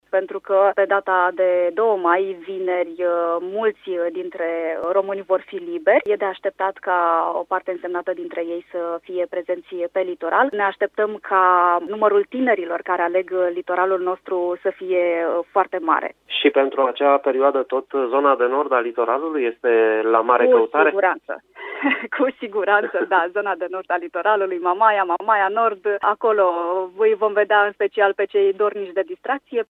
Directorul de marketing și comunicare al unei agenții de turism